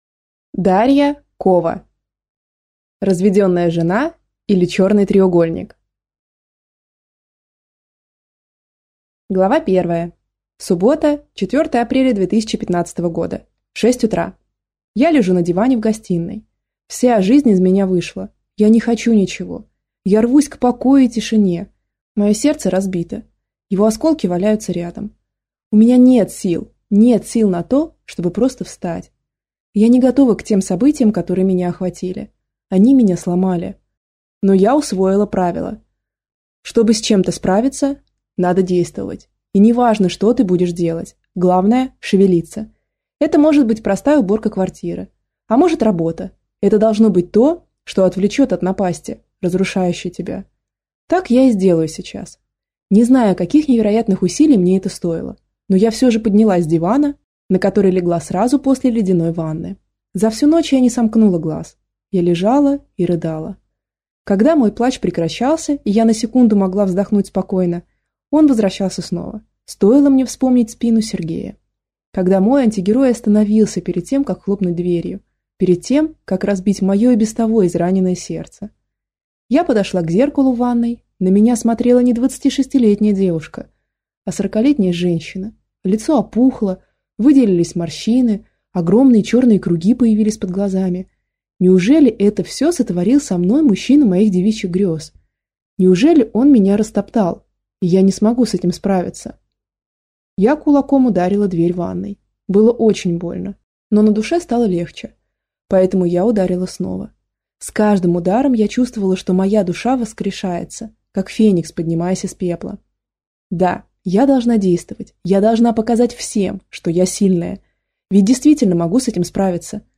Аудиокнига Разведенная жена, или Черный треугольник | Библиотека аудиокниг